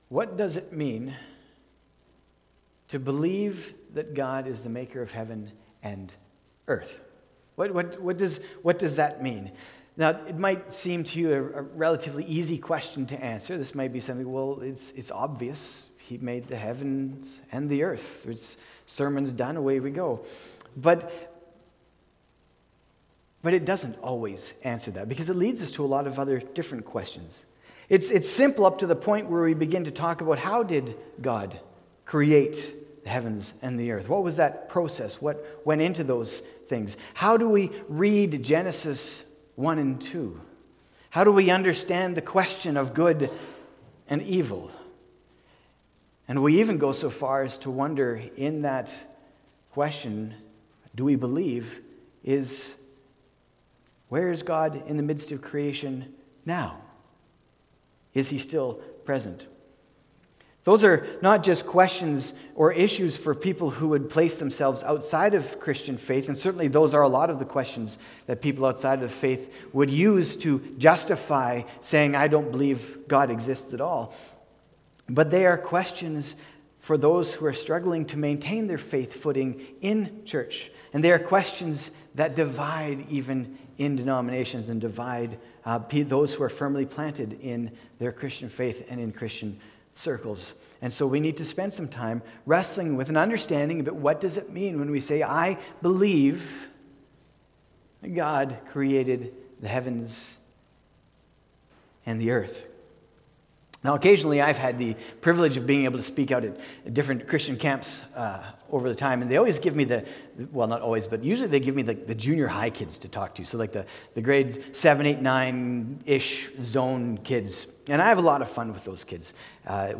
march-1-2026-sermon.mp3